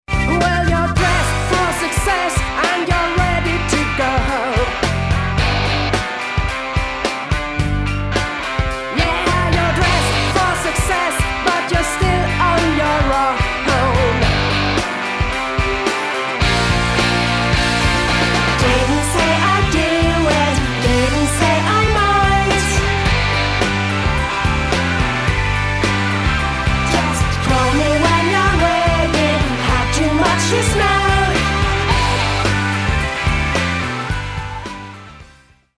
I say their music is Alt Pop Rock